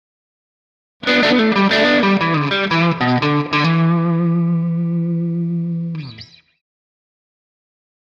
Electric Guitar
Blues Guitar - Short Solo 1 - Blues End